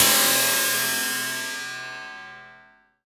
Index of /90_sSampleCDs/Optical Media International - Sonic Images Library/SI2_SI FX Vol 7/SI2_Gated FX 7
SI2 CRASH05L.wav